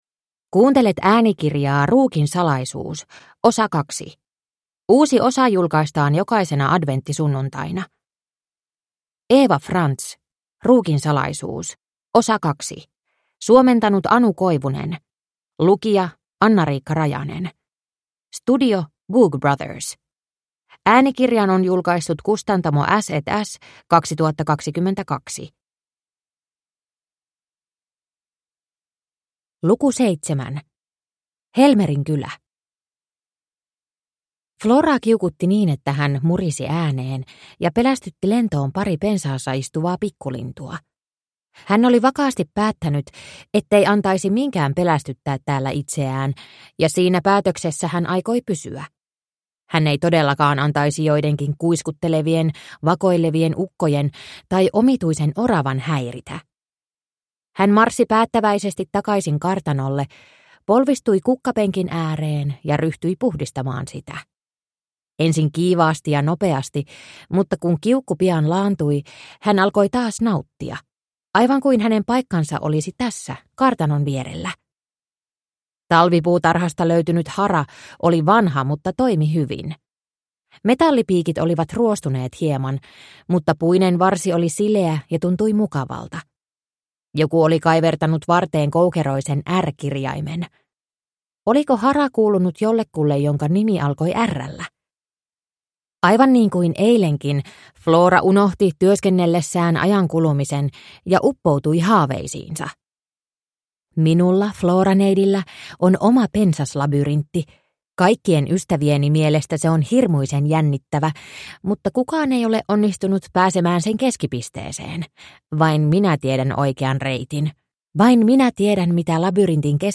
Ruukin salaisuus osa 2 – Ljudbok – Laddas ner
Entä mitä tapahtui sen omistaneelle von Hiemsin perheelle?Kihelmöivän jännittävä äänikirja on jaettu neljään osaan, jotka julkaistaan viikon välein jolukuussa.